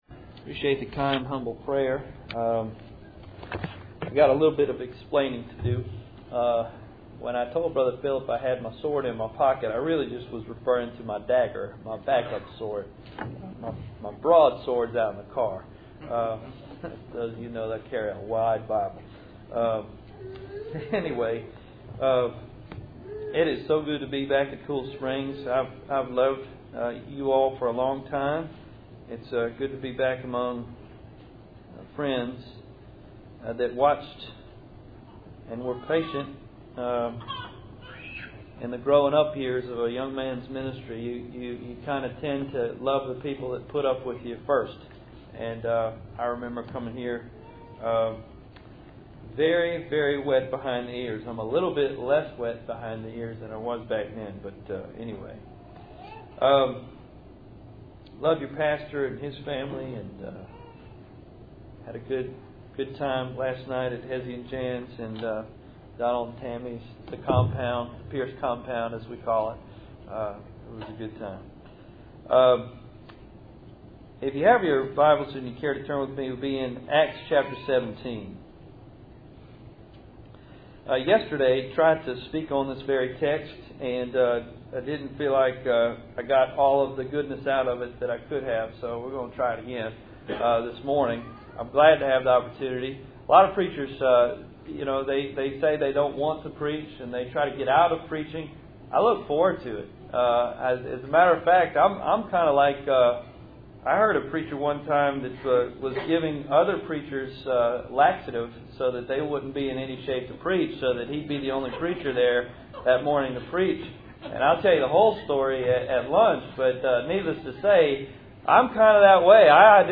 Acts 27:22-34 Service Type: Cool Springs PBC Sunday Morning %todo_render% « II Timothy 2:11-13